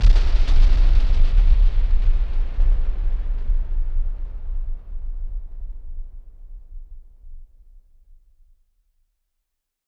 BF_SynthBomb_D-06.wav